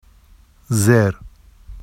[zer] adv down